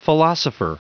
Prononciation du mot philosopher en anglais (fichier audio)
Prononciation du mot : philosopher
philosopher.wav